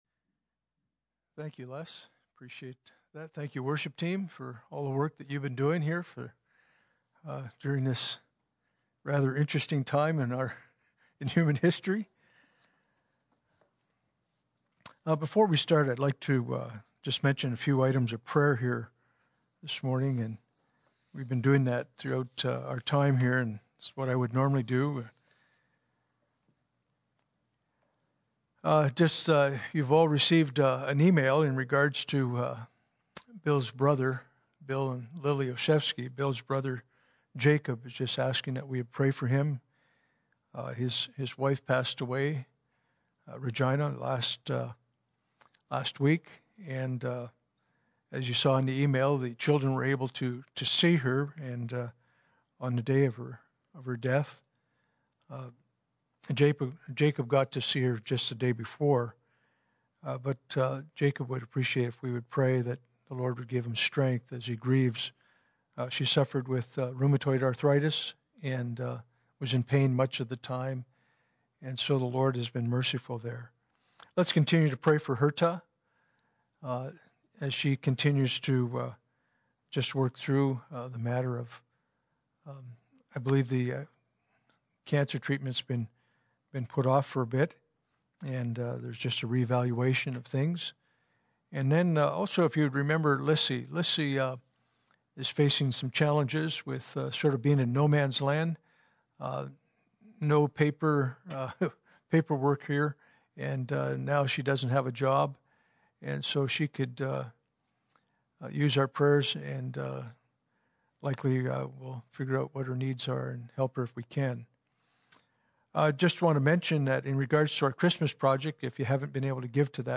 Passage: Matthew 1:18-25 Service Type: Sunday Morning